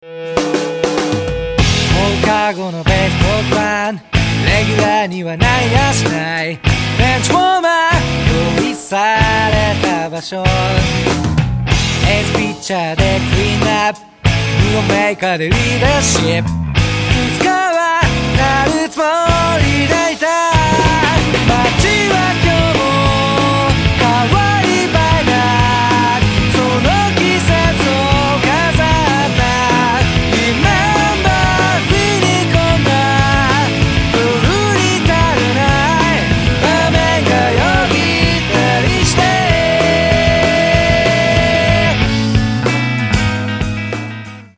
どこまでもエヴァー・グリーンなパワー・ポップ！！！！
素朴なアコースティックギターに続き、バイオリンとヴォーカルが絶妙にリンクする、本作で最もドラマチックな展開。